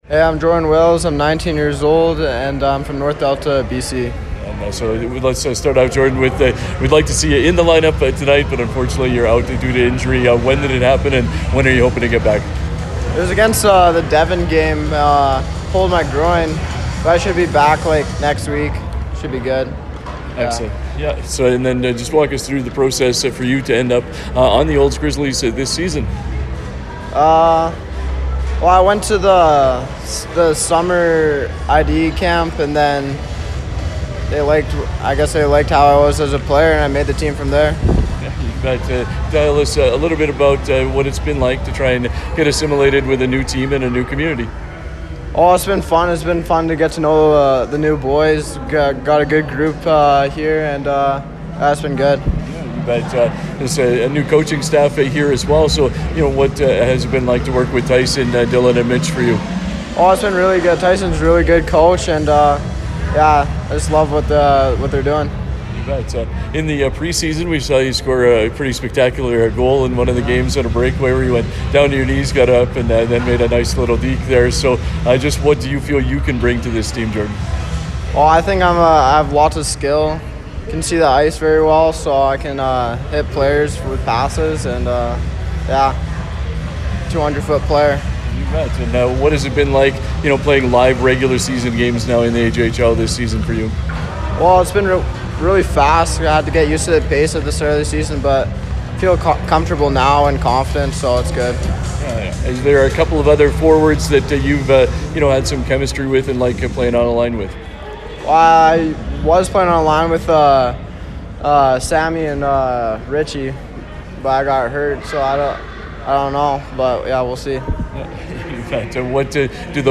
Prior to the game, 96.5 The Ranch spoke with a couple players out of the line up for the Olds Grizzlys.